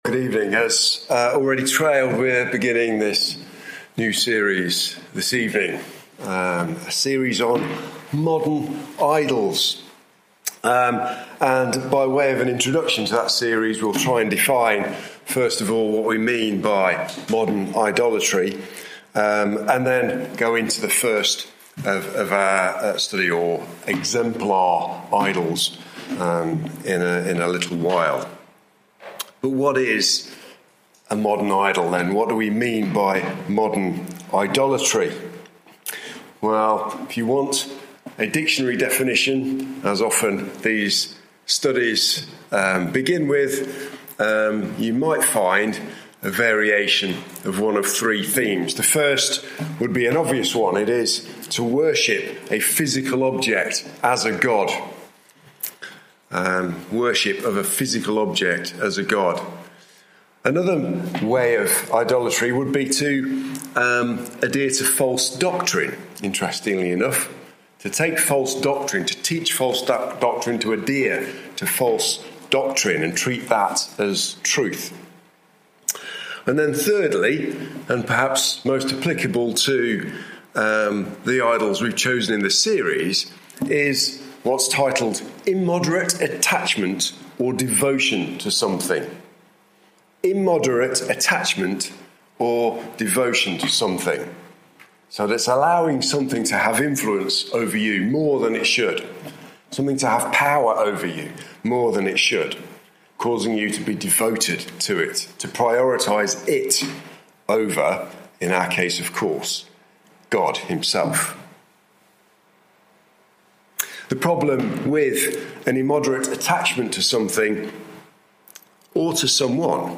at our Sunday evening service on the 6th April 2025
Topical Bible studies